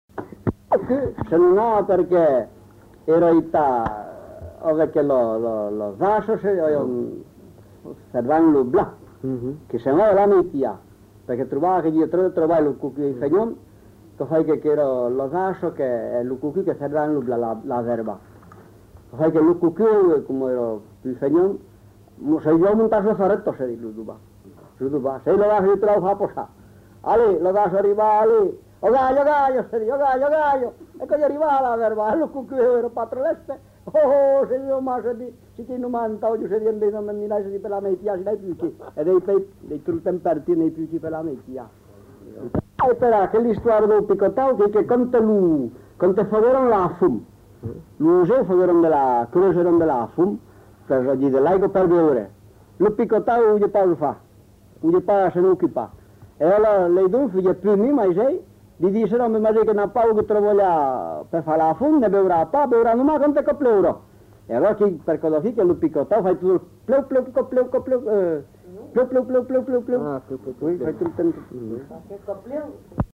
Aire culturelle : Périgord
Genre : conte-légende-récit
Effectif : 1
Type de voix : voix d'homme
Production du son : parlé